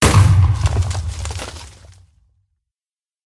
苦力怕：爆炸
苦力怕在爆炸播随机放这些音效。
Minecraft_Creeper_explosion4.mp3